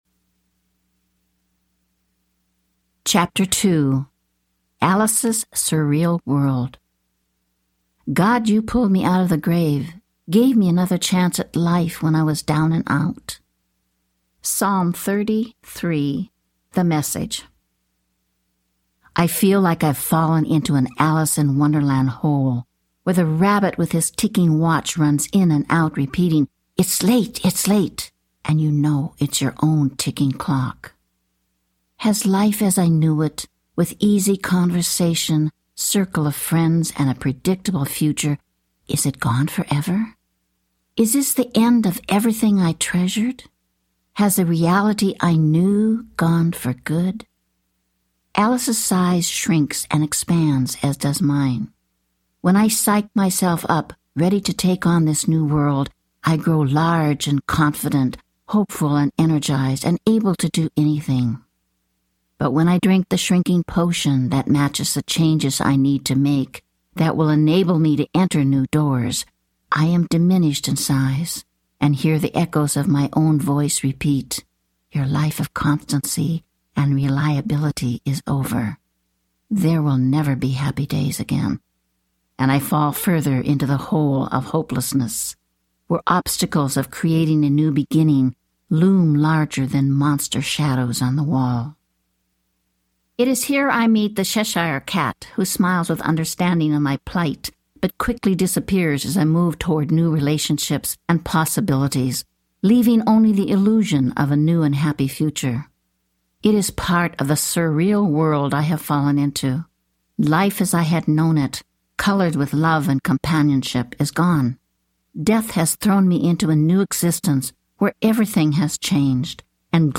Learning to Live Again in a New World Audiobook
Narrator
4.2 Hrs. – Unabridged